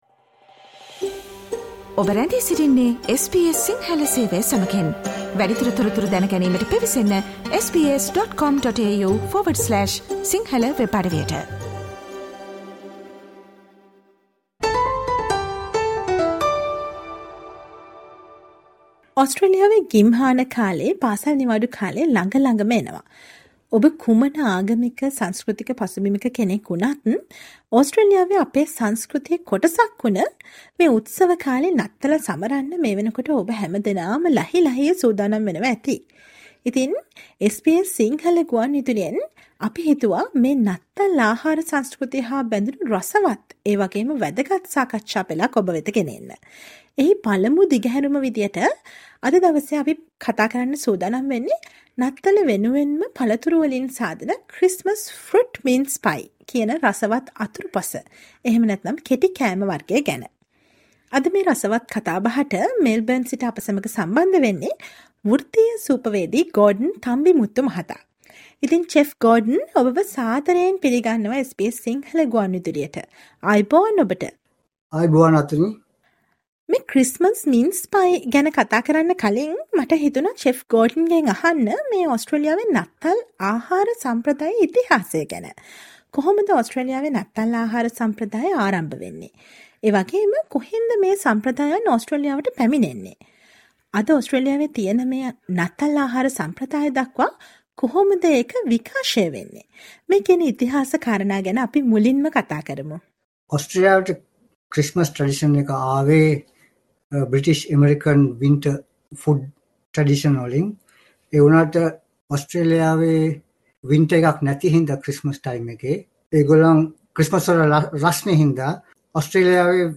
නත්තල් කාලෙට නැතුවම බැරි Christmas minced pie ගැන රසවත් කතාබහක්